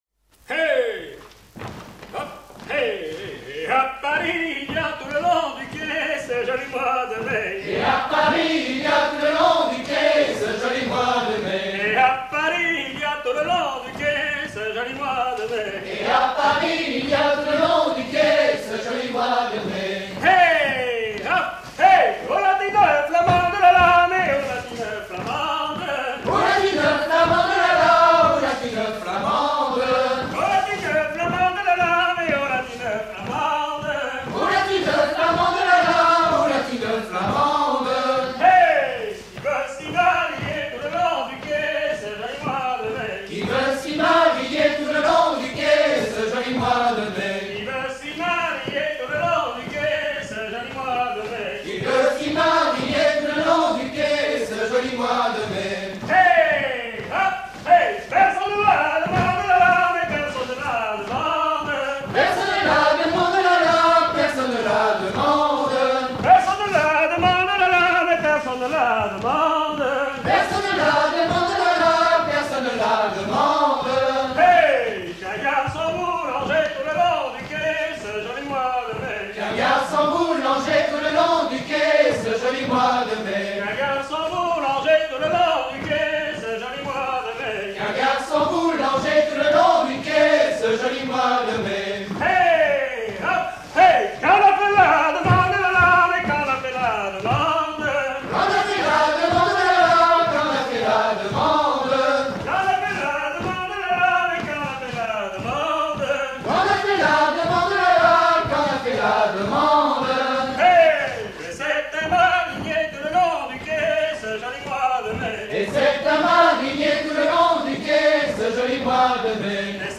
Ronde ou grand'danse
Dix danses menées pour des atelirs d'apprentissage